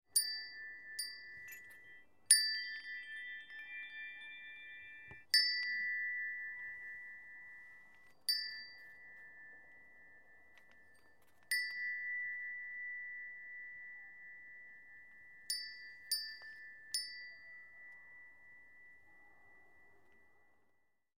Звуки колокольчиков
Приятный звон колокольчика